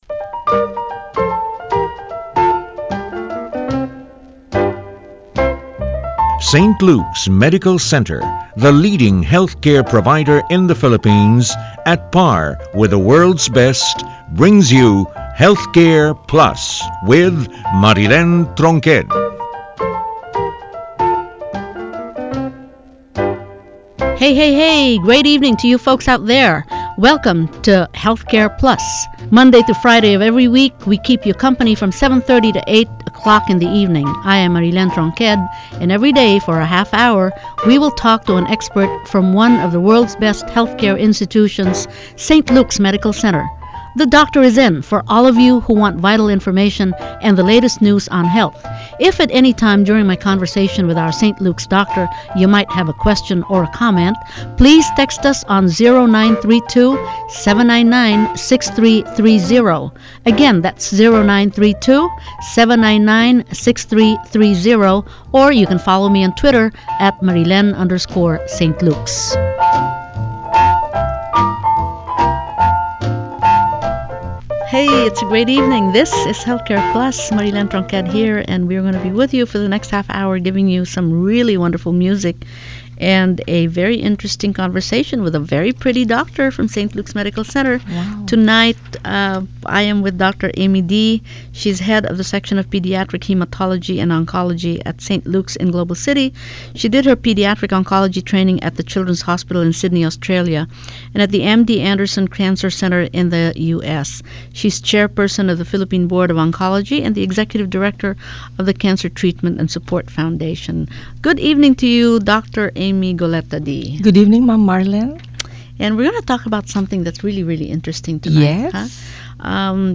Gene Therapy Interview